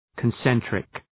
Προφορά
{kən’sentrık}